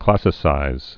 (klăsĭ-sīz)